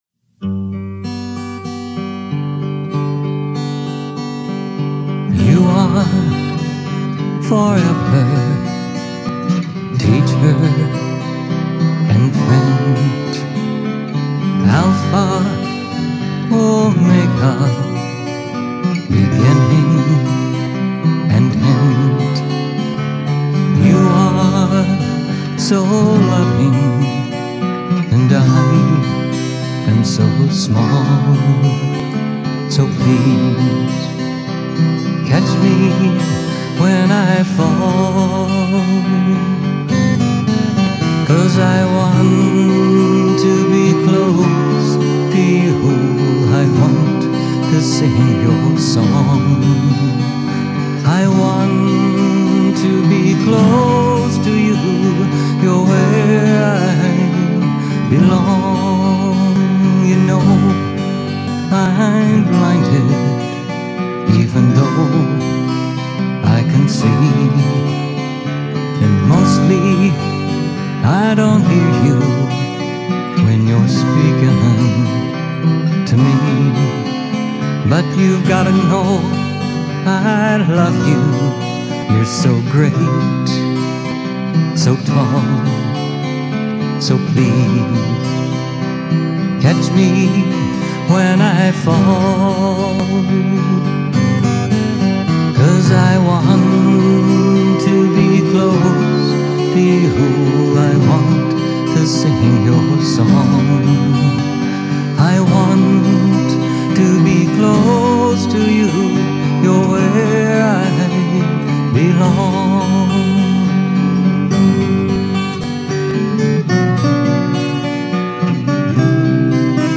Composes country, ballad and spiritual music.